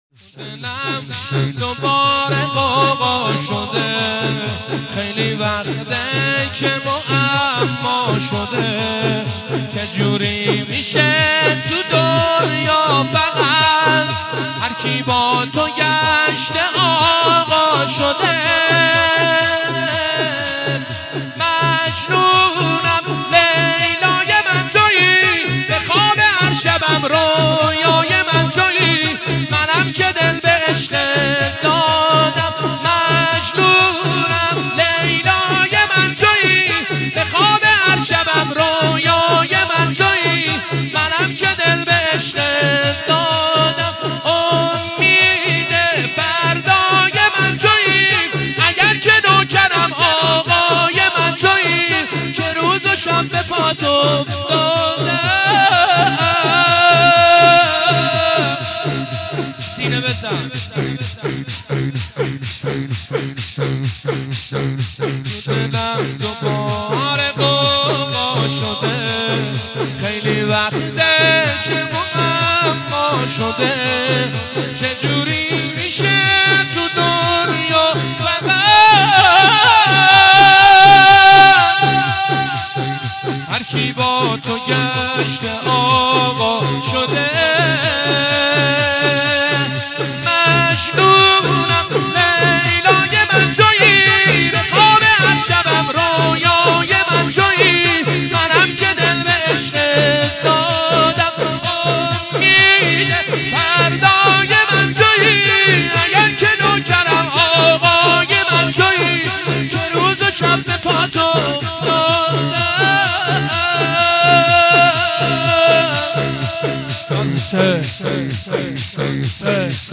شب هفتم رمضان95